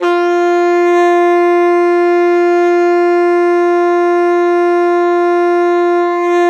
saxophone
F4.wav